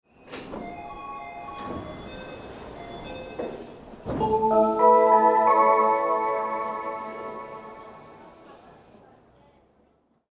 多摩都市モノレールの各駅では、放送・発車時のメロディ共にJR東日本などでよく聞かれるタイプの放送が使用されています。
tamamono_1melody.mp3